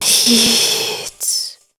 WHISPER 08.wav